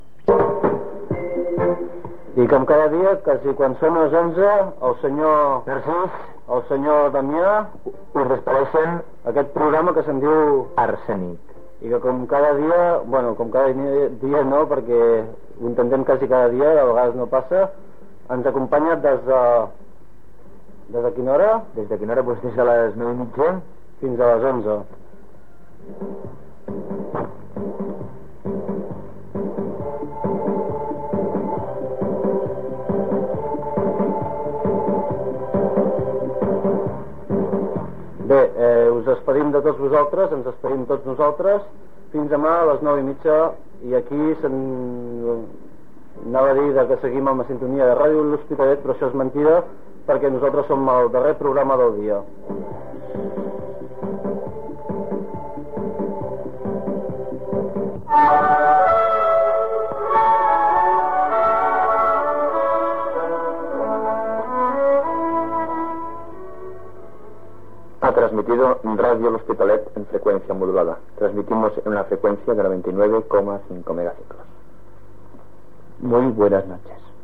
Final del programa i tancament de l'emissió.